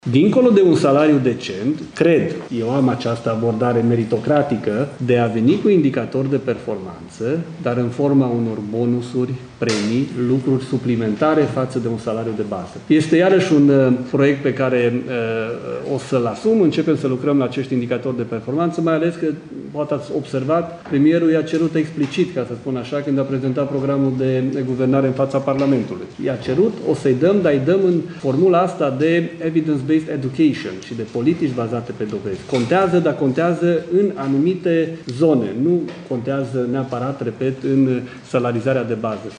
Ministrul Educației, Daniel David: Am această abordare meritocratică, de a veni cu indicatori de performanță, dar în forma unor bonusuri, premii, lucruri suplimentare față de un salariu de bază